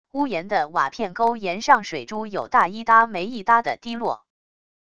屋檐的瓦片沟沿上水珠有大一搭没一搭地滴落wav音频